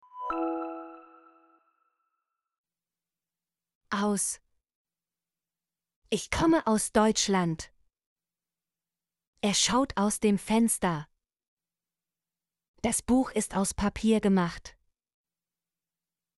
aus - Example Sentences & Pronunciation, German Frequency List